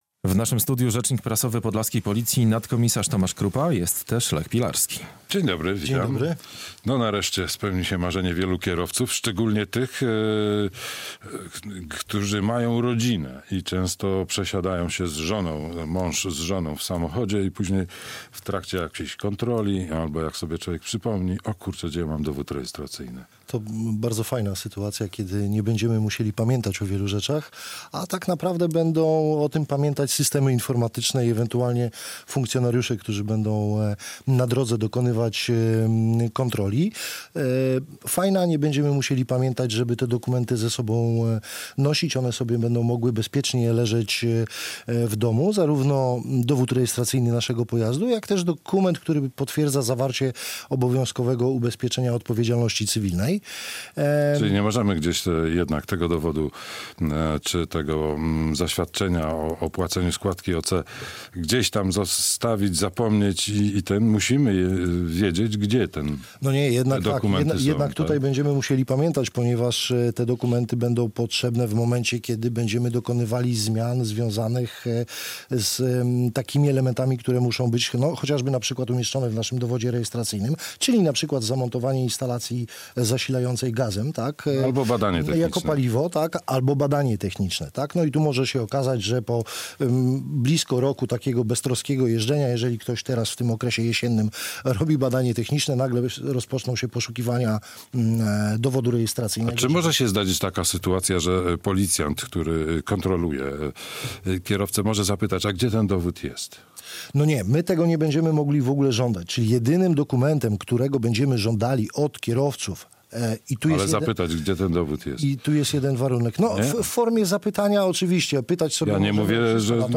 Radio Białystok | Gość | nadkom.